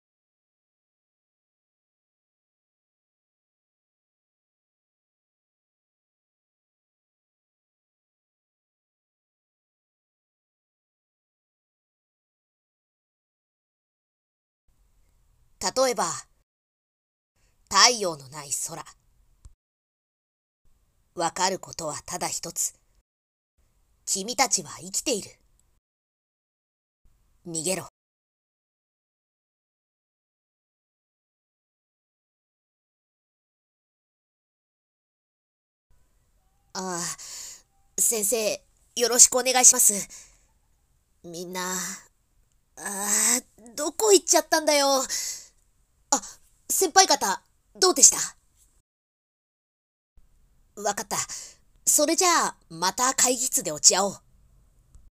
】🐦‍🔥声劇セリフ nanaRepeat